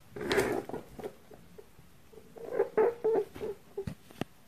Звуки кролика
Звук раздраженного кролика